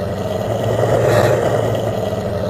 ghoul.ogg